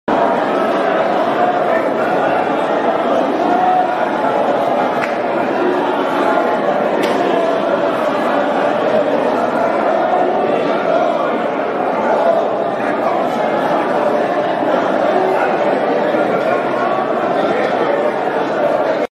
נוצרים בטקס אלילי של 'דיבור בלשונות' בחדר הסעודה האחרונה (מעל קבר דוד) ולמרות החוק הישראלי האוסר זאת